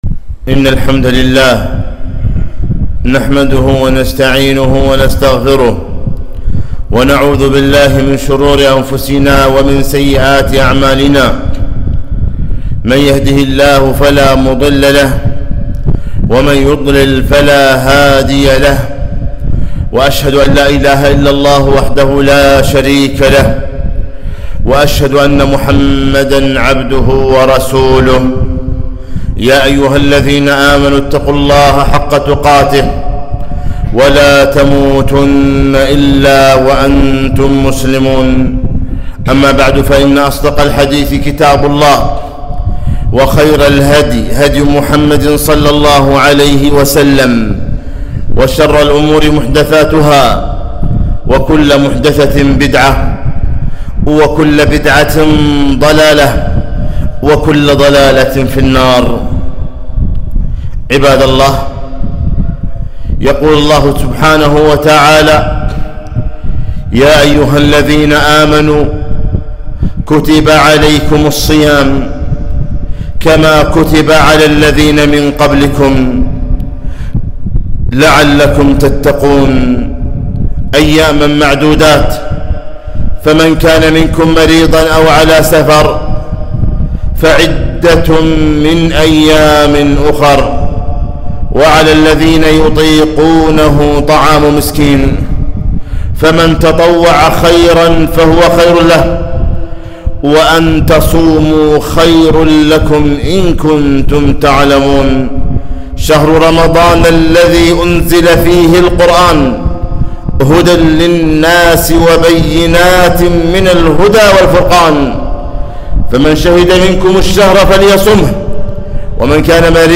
خطبة - ملخص أحكام الصيام